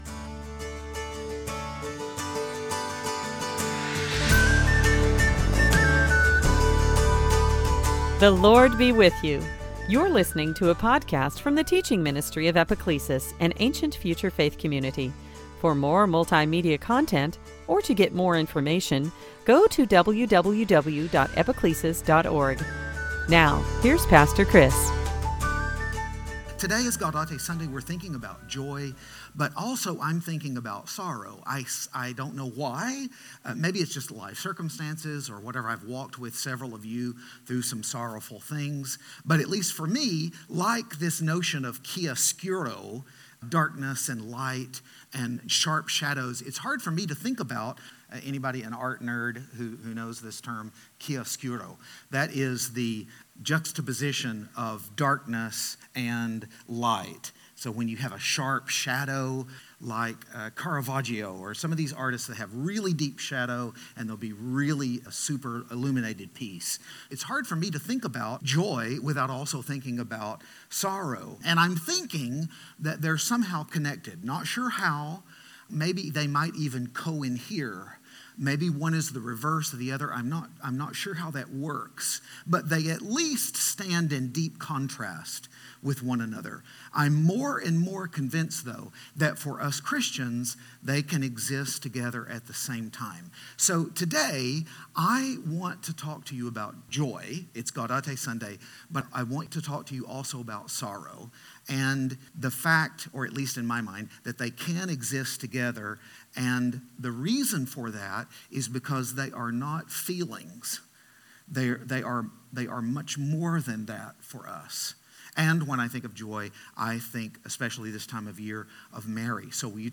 It was the third Sunday, "Gaudete" or Joy Sunday, at Epiclesis.